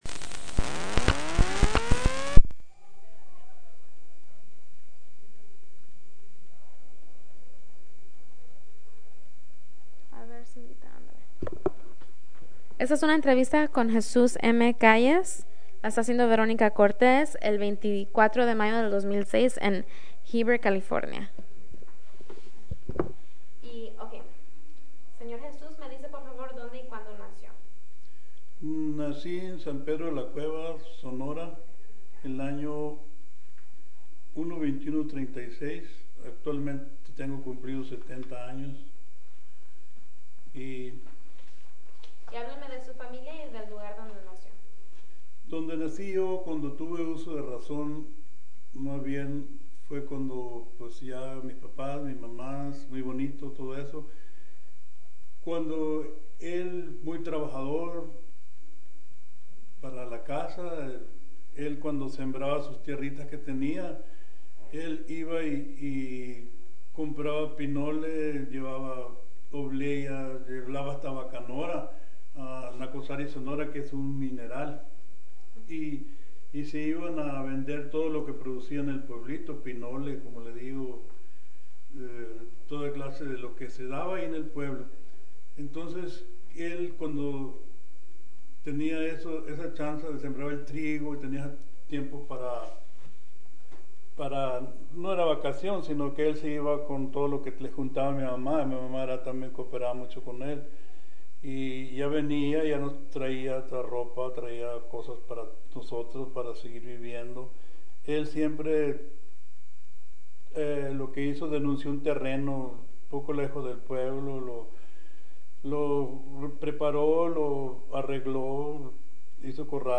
Location Heber, CA